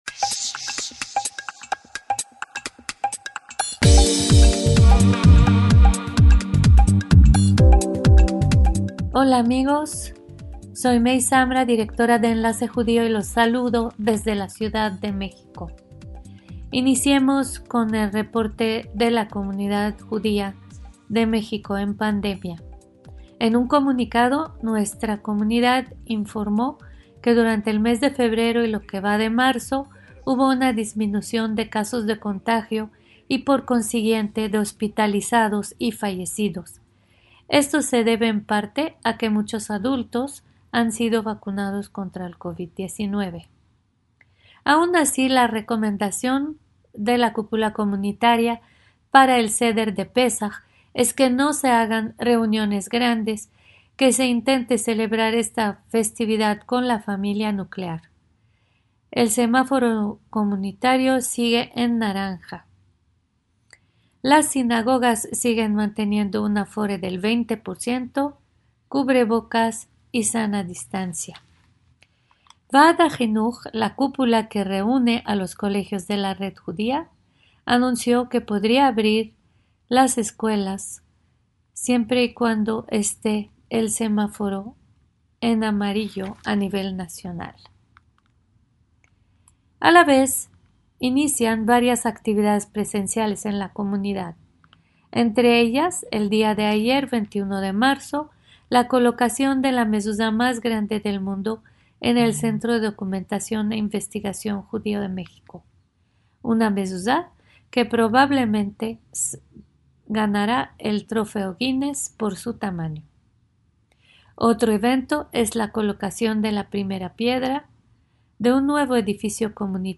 DESDE MÉXICO, CON ENLACE JUDÍO - Desde la Ciudad de México